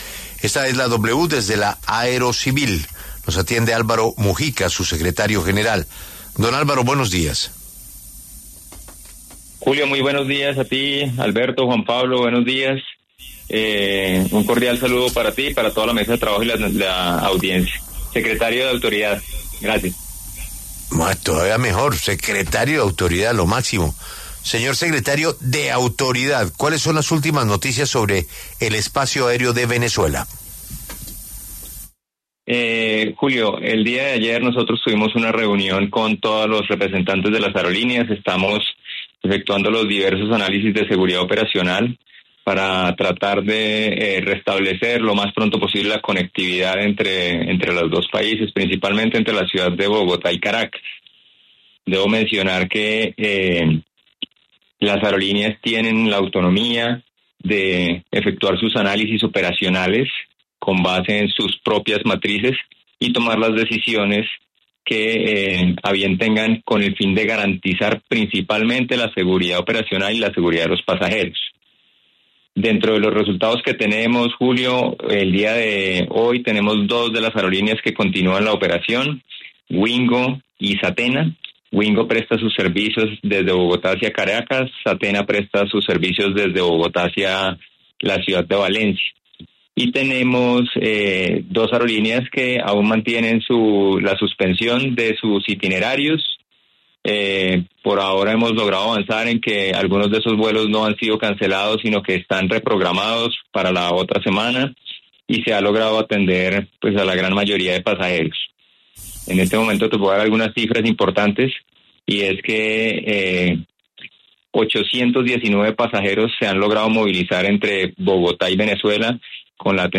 En entrevista con W Radio, el coronel (r) Álvaro Mujica, secretario de Autoridad de la Aeronáutica Civil, aseguró que ante la situación que se registra de alerta con el espacio aéreo de Venezuela, se está trabajando con todas las aerolíneas que operan desde Colombia al vecino país para establecer los verdaderos riesgos de seguridad que se pueden enfrentar y así mismo restablecer las operaciones aéreas que se han visto afectadas en las recientes jornadas.